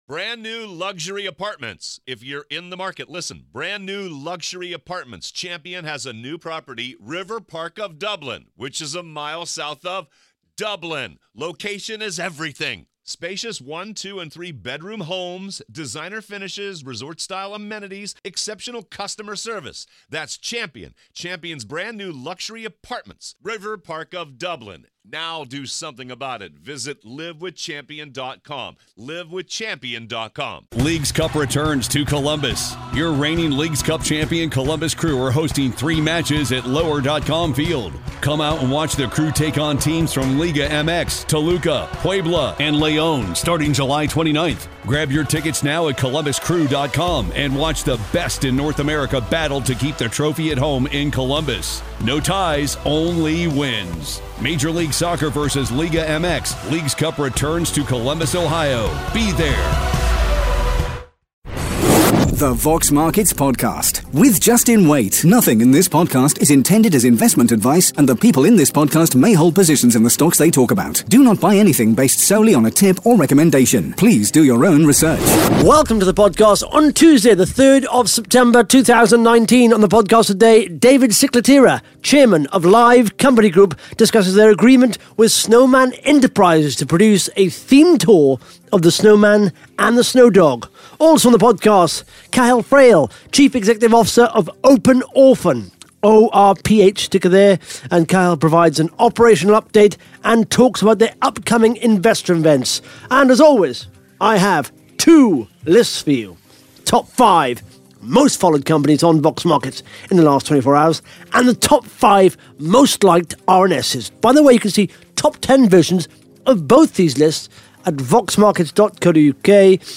(Interview starts at 8 minutes 28 seconds) Plus the Top 5 Most Followed Companies & the Top 5 Most Liked RNS’s on Vox Markets in the last 24 hours.